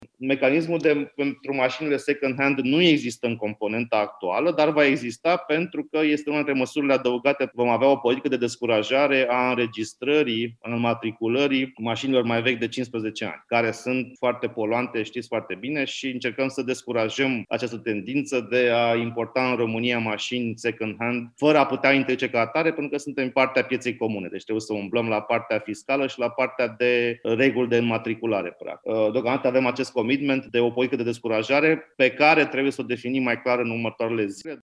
Ministrul Investițiilor și Proiectele Europene a explicat vineri, la o dezbatere organizată de Expert Forum, că importurile vor fi reduse prin măsuri fiscale și prin noi reguli la înmatricularea mașinilor vechi.